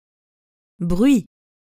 Pronunciation
Le bruit /ə bʁɥi/